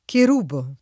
ker2bo o